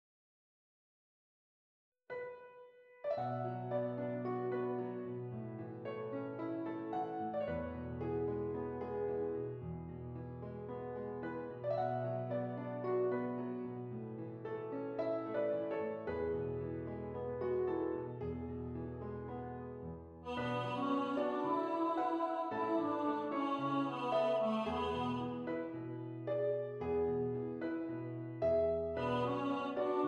A Major
Moderate